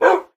minecraft / sounds / mob / wolf / bark3.ogg
bark3.ogg